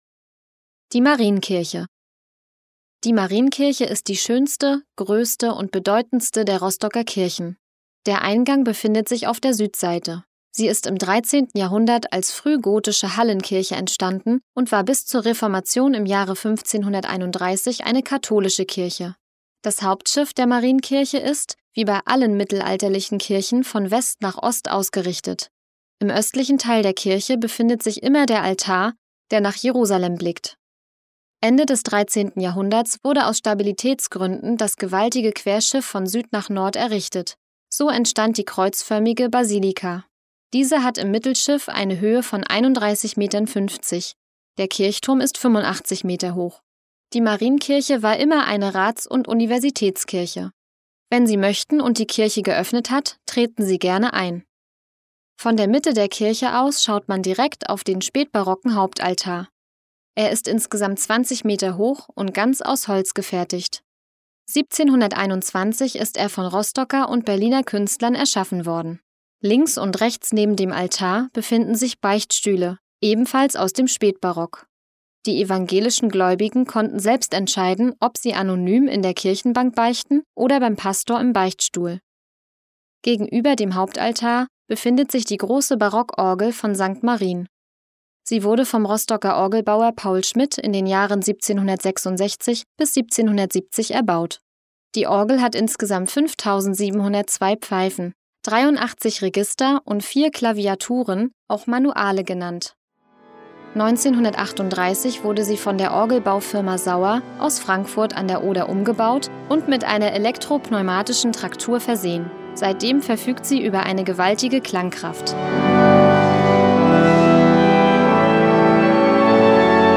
Audioguide
Audioguide Rostock - Station 9: St. Marienkirche